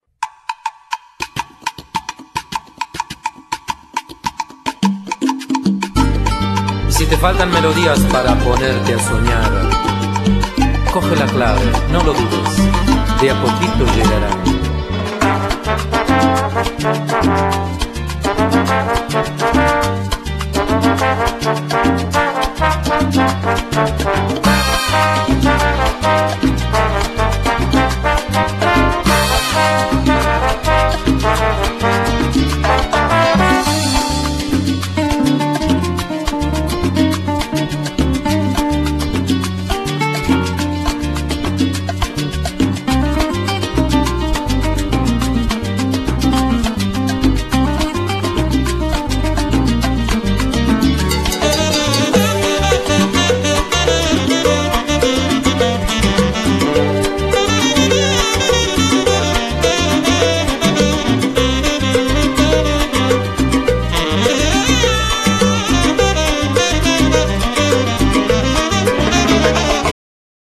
Genere : Latin